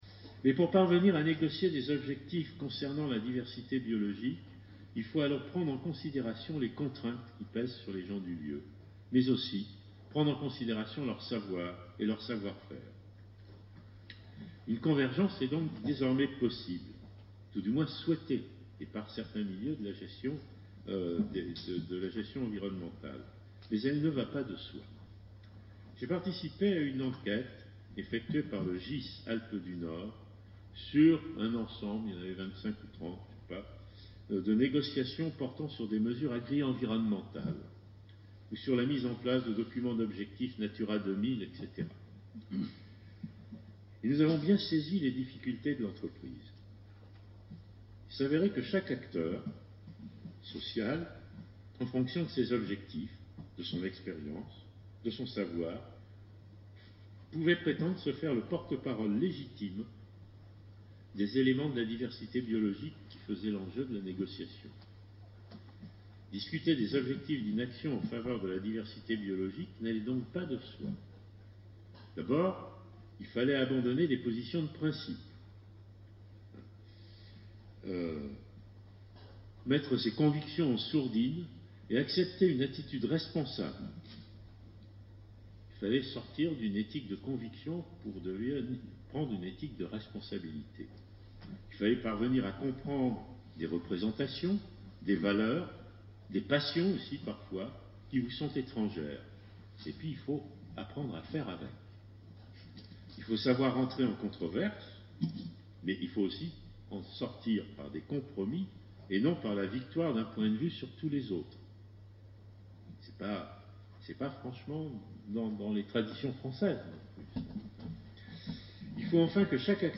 L’association Sol et Civilisation s’est associée à l’association Nature et Paysages et au Forum de l’agriculture raisonnée respectueuse de l’environnement (FARRE Mayenne) pour éclairer ces questions lors d’une soirée d’échanges et débats à Château-Gontier le 4 novembre 2010.